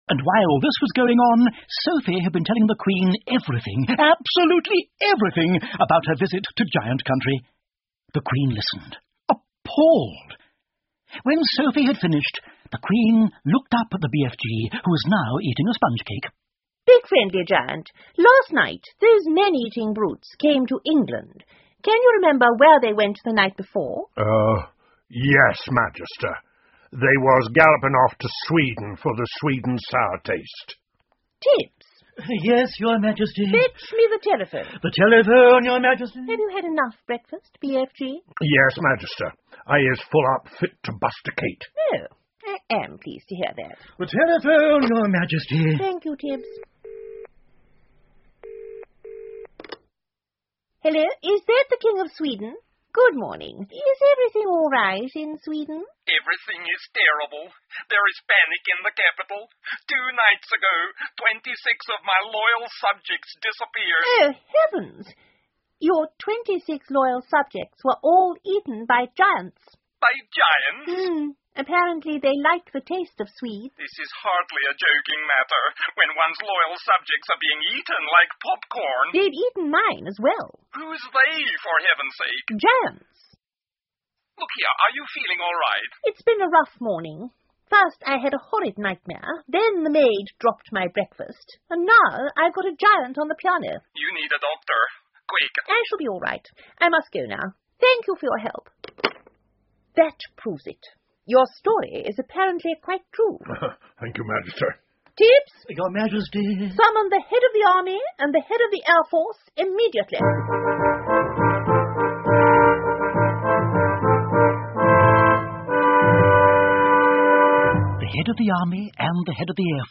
The BFG 好心眼儿巨人 儿童广播剧 16 听力文件下载—在线英语听力室